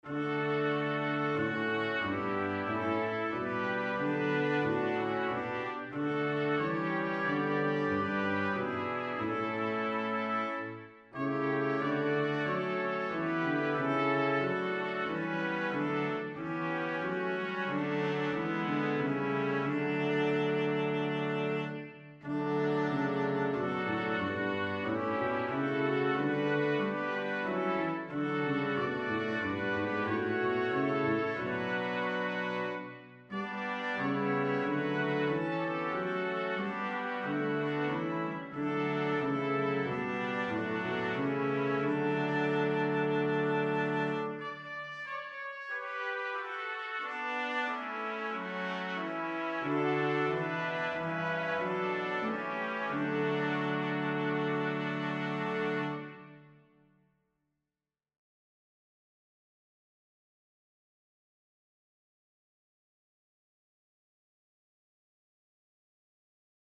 Koperkwintet – mp3